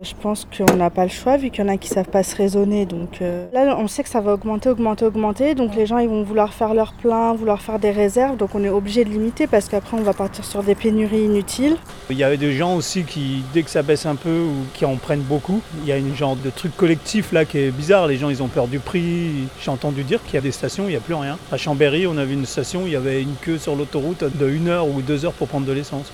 Nous vous avons posé la question, devant la pompe, ces derniers jours en Haute-Savoie.